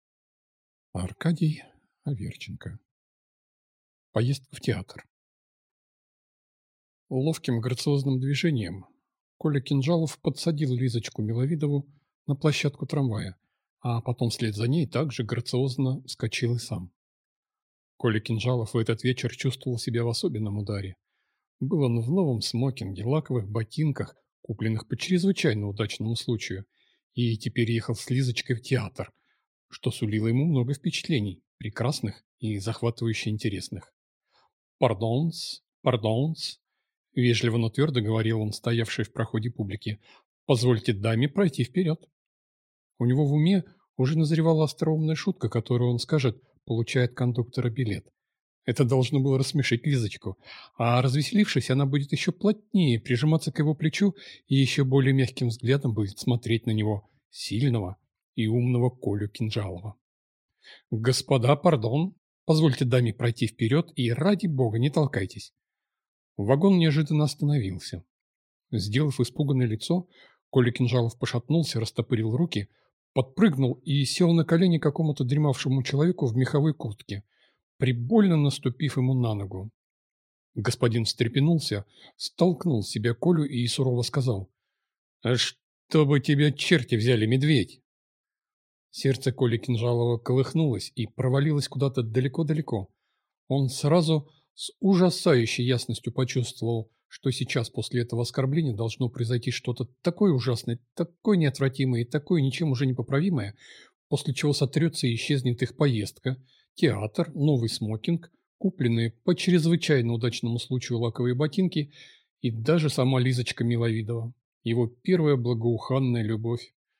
Аудиокнига Поездка в театр | Библиотека аудиокниг